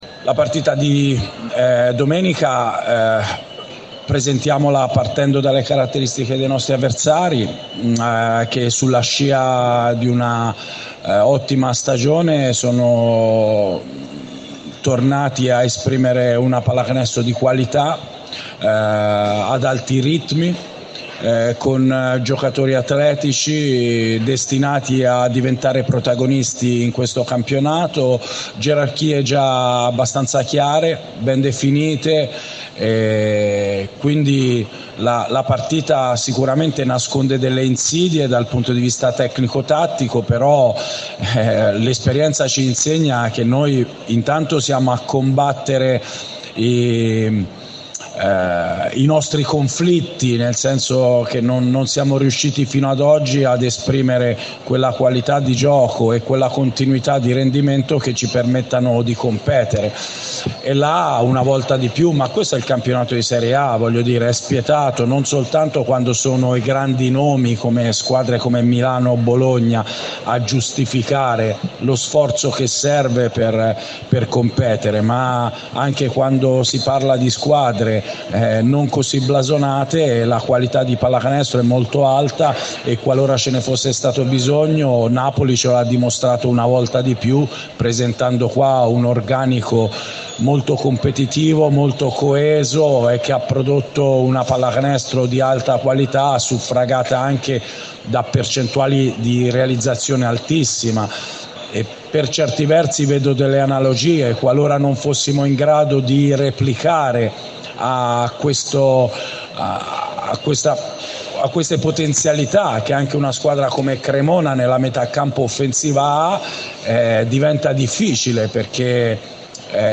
Ai nostri microfoni, il Coach della Carpegna Prosciutto Basket Pesaro Luca Banchi, ha presentato il posticipo della 9^ giornata del campionato di Serie A che domenica 21 novembre alle 20:45 al PalaRadi vedrà i biancorossi sfidare la Vanoli Cremona.